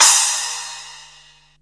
CRASH02   -R.wav